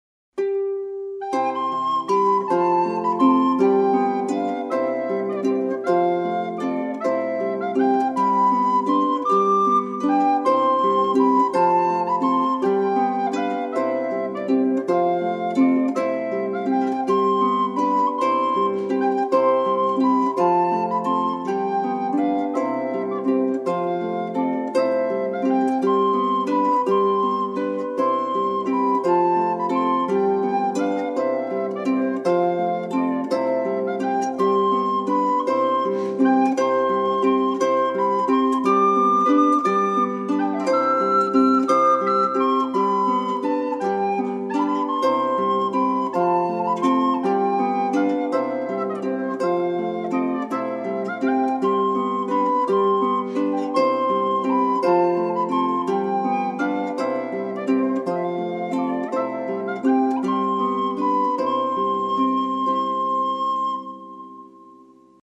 (Celtic harp, flute)  1'051.00 MB1.70 Eur